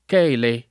chele [ k $ le ]